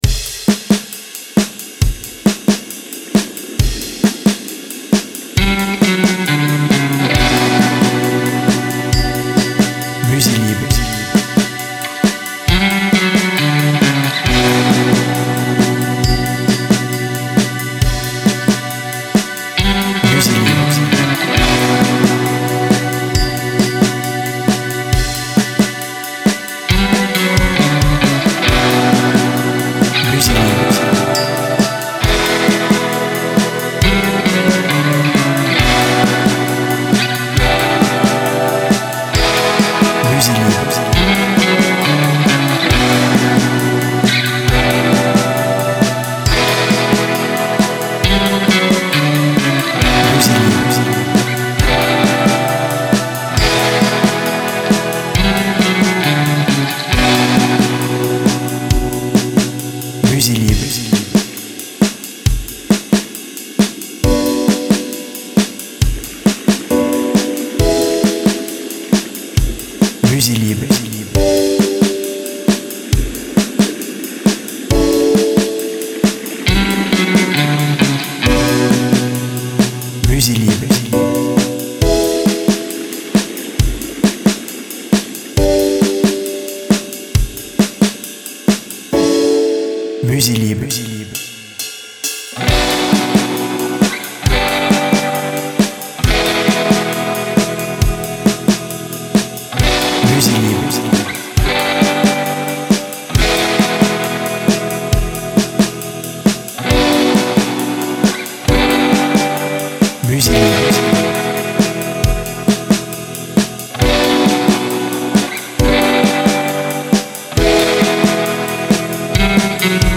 BPM Moyen